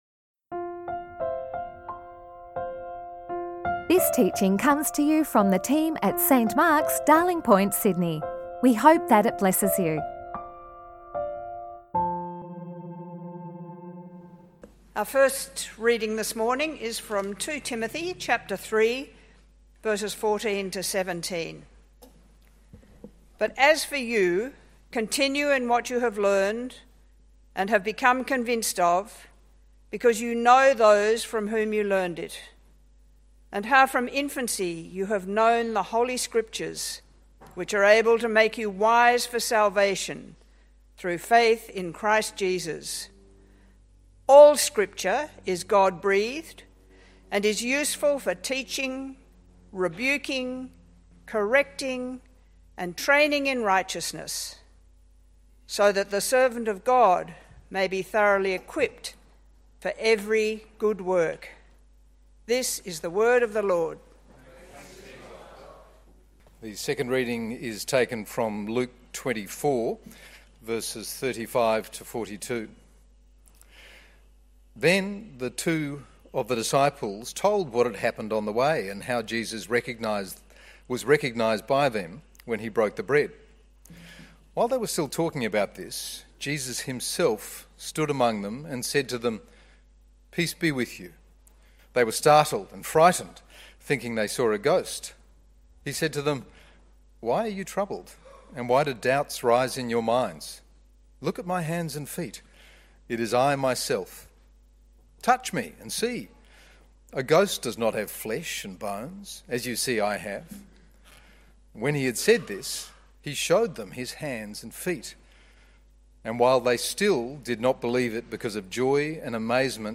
Weekly sermons recorded at St Mark's Darling Point in Sydney, Australia.